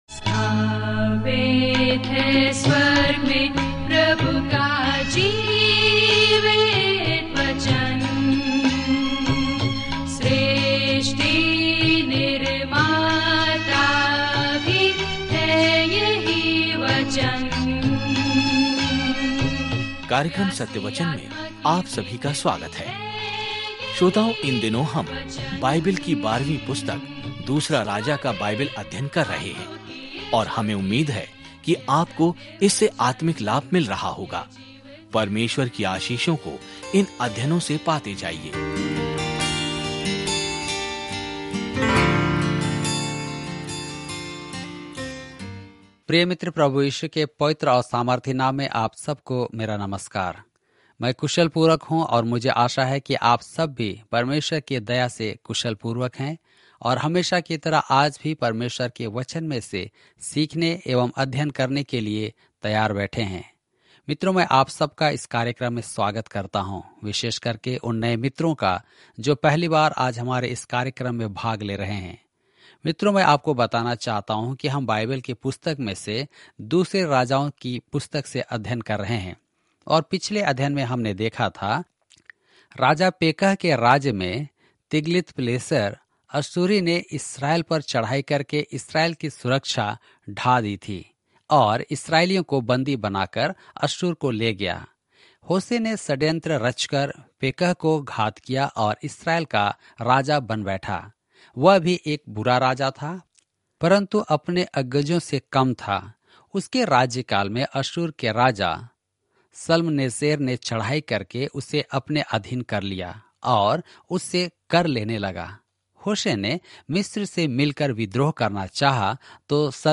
पवित्र शास्त्र 2 राजाओं 18 दिन 8 यह योजना प्रारंभ कीजिए दिन 10 इस योजना के बारें में सेकेंड किंग्स की किताब बताती है कि कैसे लोगों ने भगवान की दृष्टि खो दी और कैसे वह उन्हें कभी नहीं भूले। जब आप ऑडियो अध्ययन सुनते हैं और भगवान के वचन से चुनिंदा छंद पढ़ते हैं तो 2 राजाओं के माध्यम से दैनिक यात्रा करें।